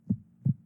Single Heart Beat.wav